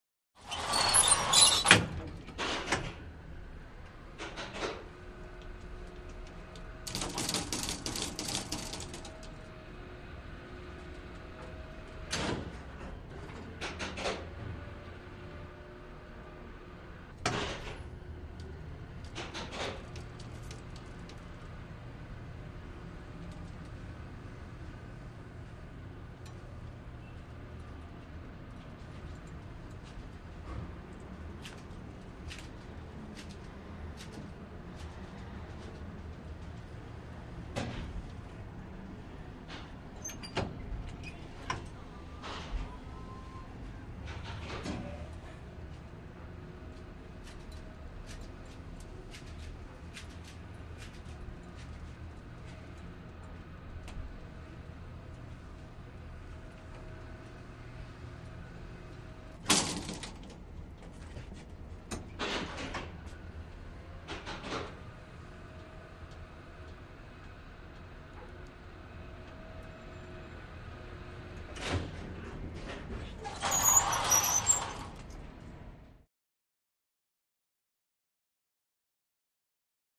Elevator - Older Style, Clanky & Squeaky